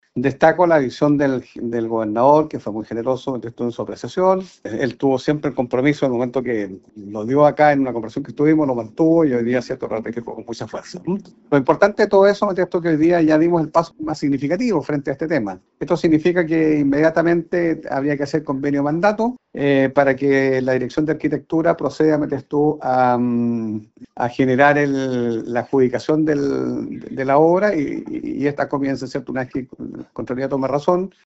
Luego de la exposición de los consejeros, el aumento presupuestario se aprobó, lo que fue celebrado por el alcalde Jaime Bertín.